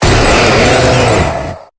Cri de Séracrawl dans Pokémon Épée et Bouclier.